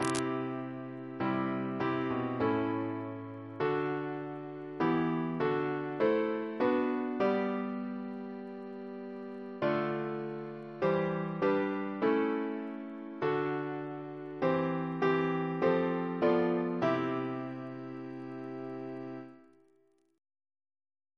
Double chant in C Composer: William H. Harris (1883-1973) Reference psalters: ACP: 16; RSCM: 66